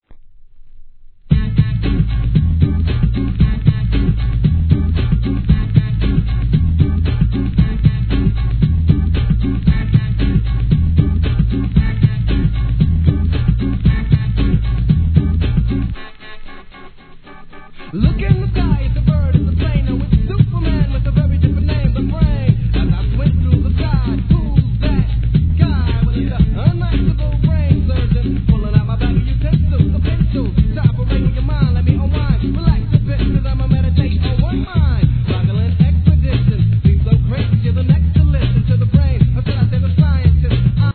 HIP HOP/R&B
チープな中にも感じ取れるものこそ、'90年代初期のHIPHOPでしょう！